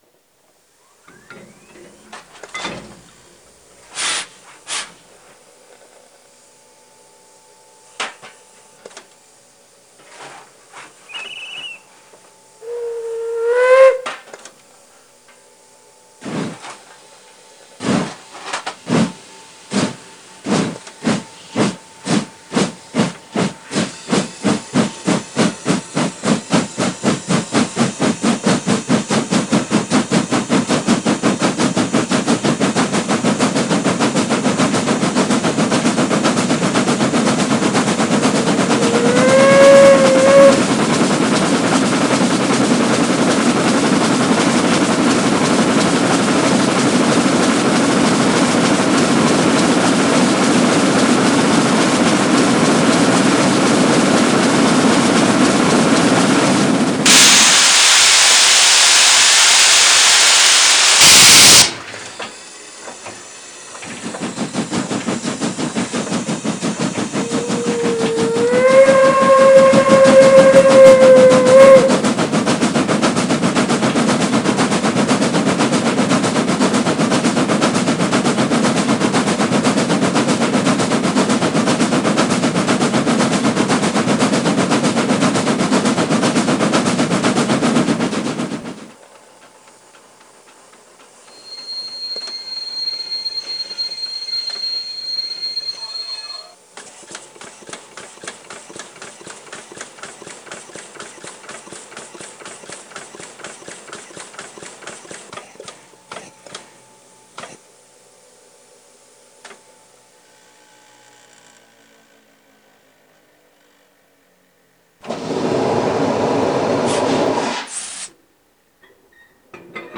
DB Baureihe 001 NBK DB Baureihe 01 Neubaukessel dampf
2 Zyl.